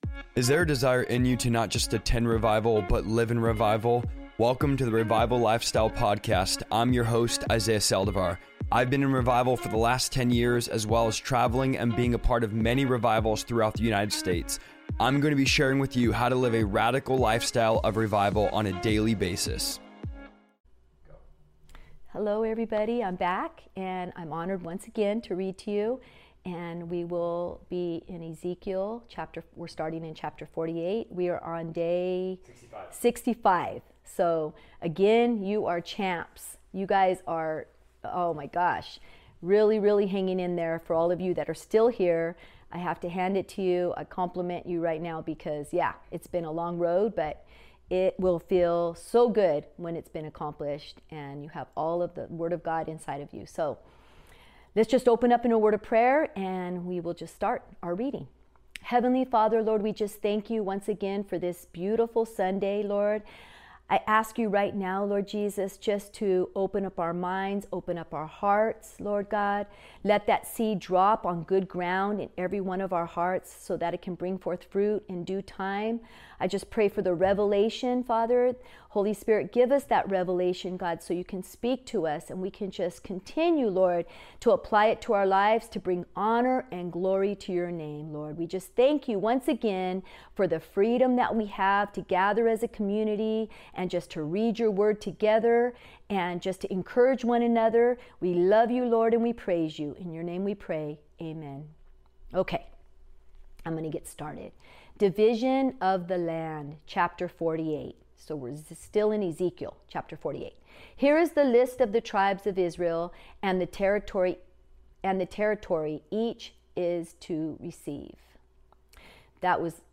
Every day, we'll dive into Scripture together in a relaxed, interactive live session where you can ask questions, share thoughts, and explore the Bible in a way that feels personal and genuine. Here’s what you can look forward to: Daily Bible Reading: I'll be reading through the Bible live, sharing my insights and reflections as we move chapter by chapter.